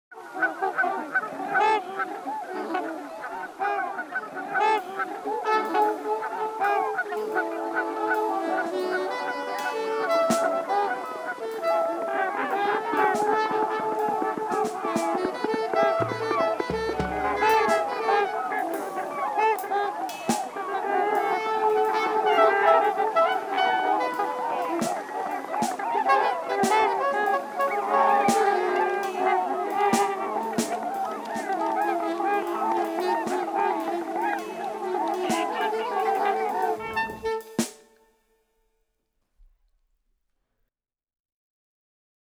on the trumpet joins trumpeter swans
clarinet
percussion